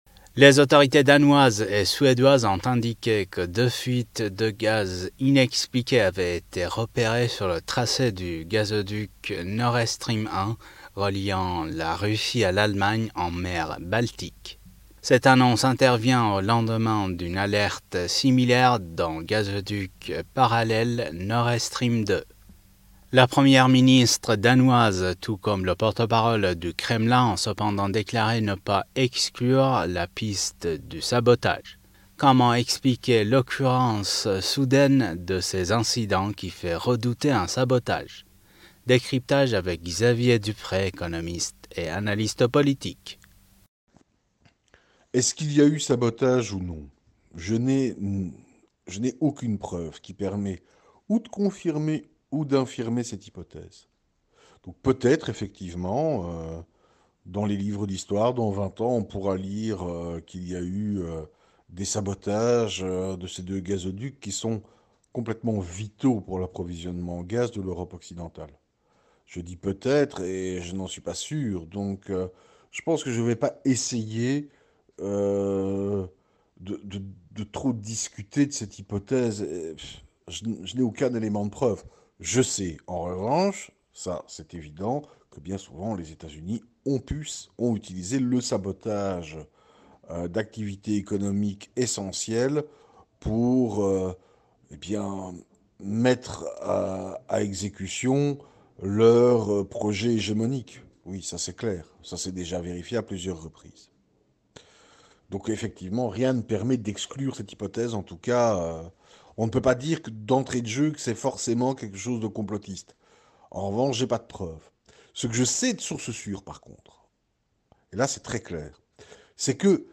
Mots clés Russie Ukraine Pétrole Europe interview Eléments connexes Washington donne 12 jours à Moscou / Moscou à Trump : « Ne deviens pas un Joe l’endormi !